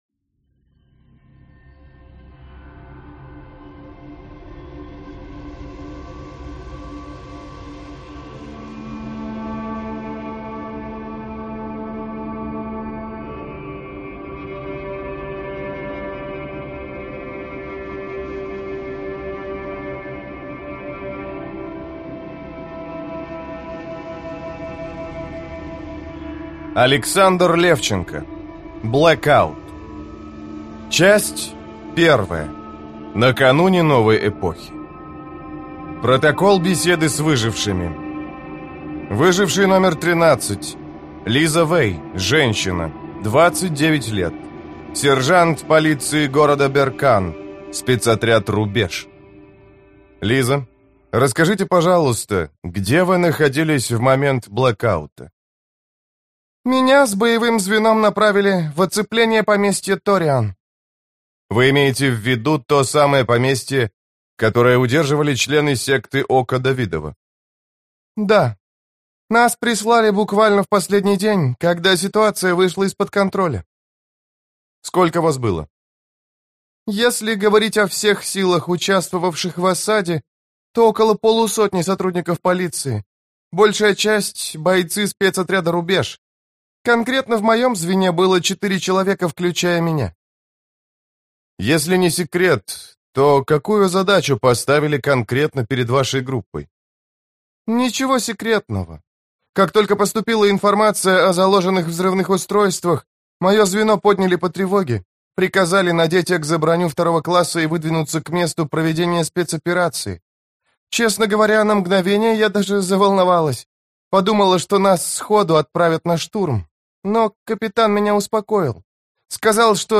Аудиокнига Блэкаут | Библиотека аудиокниг